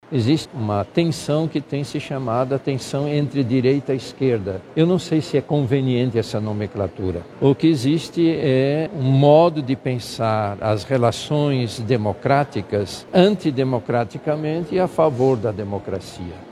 Durante uma recente declaração na Coletiva de Imprensa do Grito dos Excluídos 2025 em Manaus, o Cardeal Dom Leonardo Ulrich Steiner reforçou a importância da democracia como base para a convivência social e reafirmou a confiança nas instituições brasileiras.